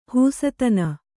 ♪ hūsatana